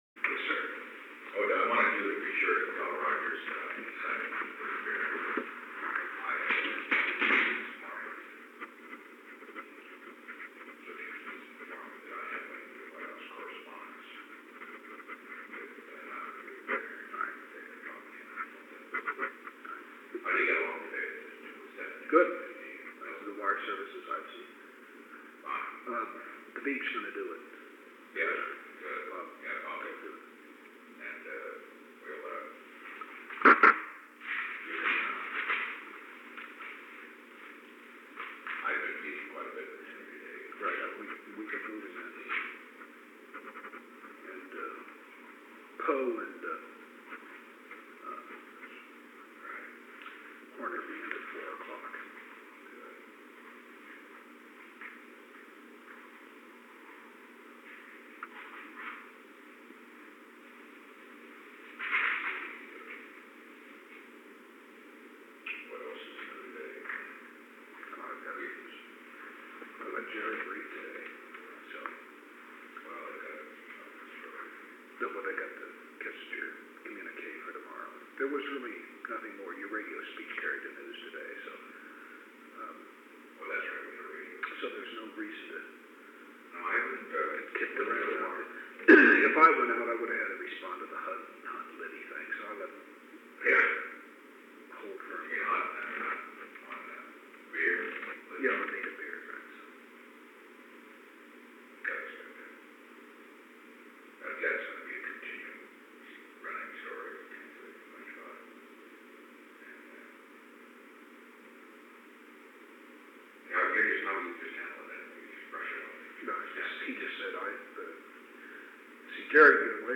Conversation: 860-009
Recording Device: Oval Office
On February 21, 1973, President Richard M. Nixon and Ronald L. Ziegler met in the Oval Office of the White House from 3:23 pm to 3:26 pm. The Oval Office taping system captured this recording, which is known as Conversation 860-009 of the White House Tapes.
The President met with Ronald L. Ziegler.